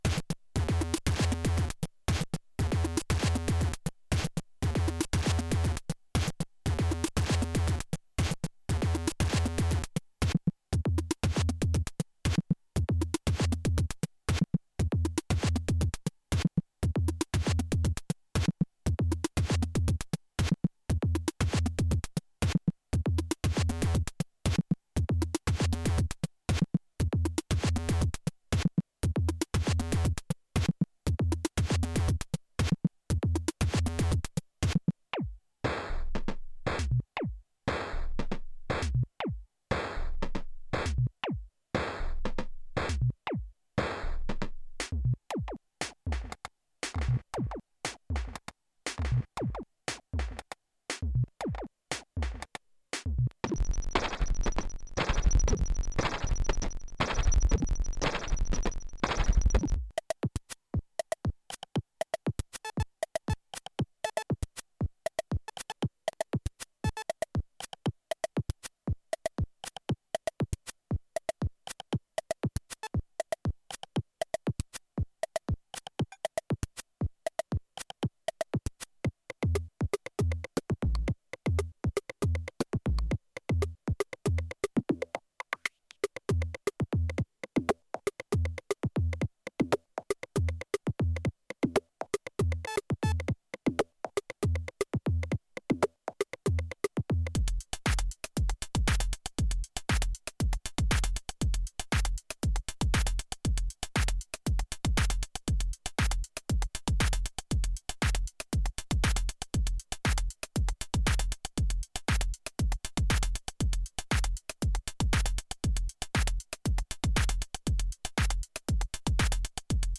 beat5
118beatlong.mp3